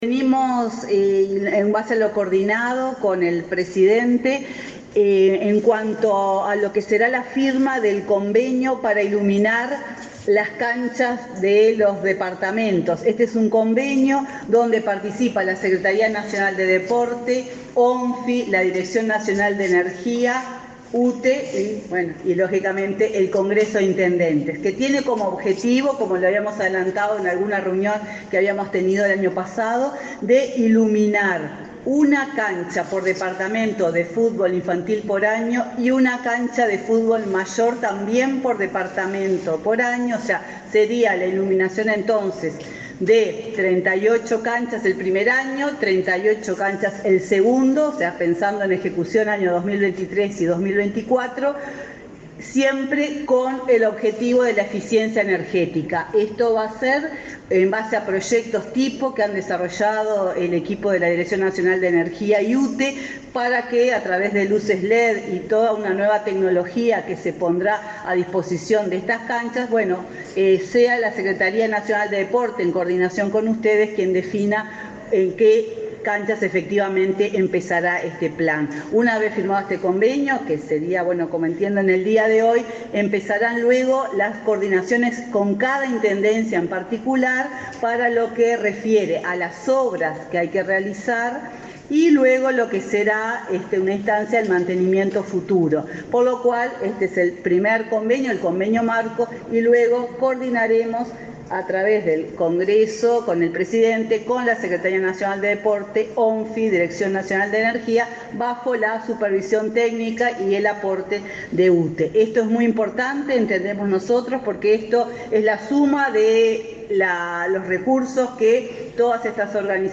Palabras de la presidenta de UTE, Silvia Emaldi